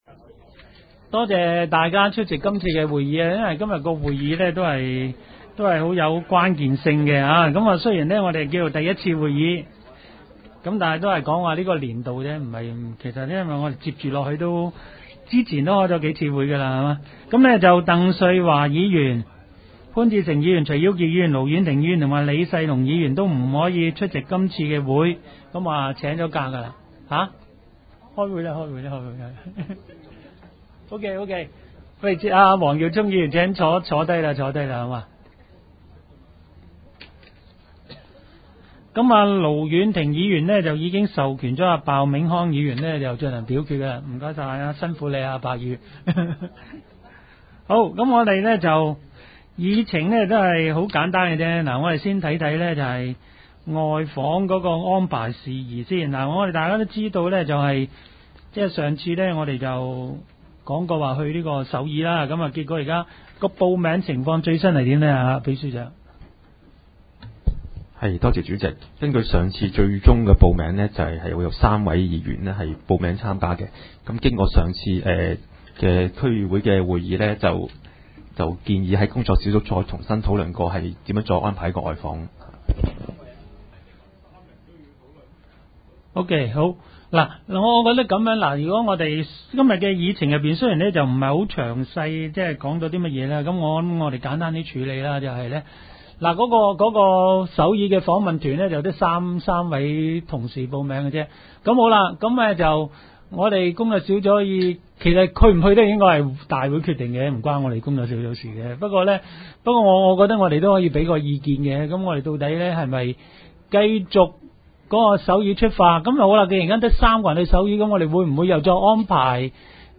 工作小组会议的录音记录
地点: 葵青民政事务处会议室